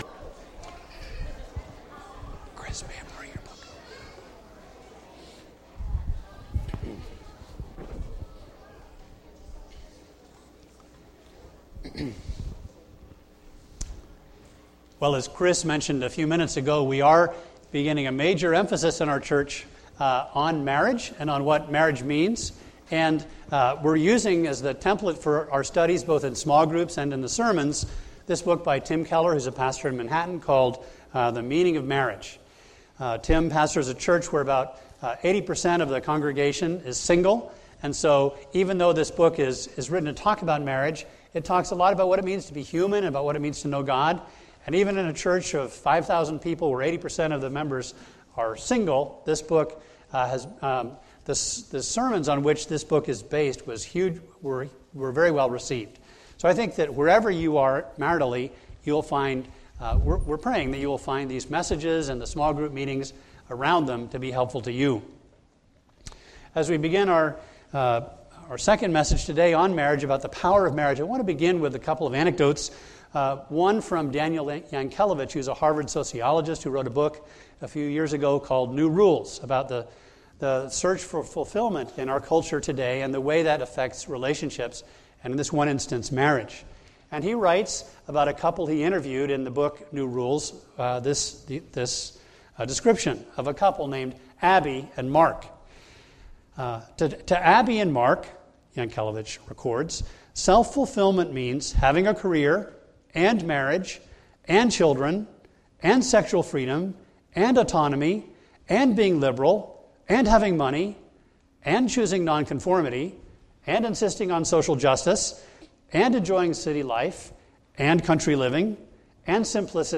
A message from the series "The Meaning of Marriage."